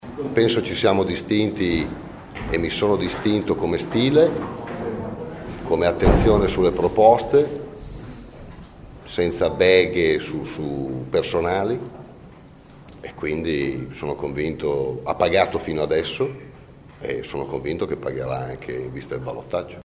Ha detto così questa mattina in una breve conferenza stampa convocata nella sede del Pd.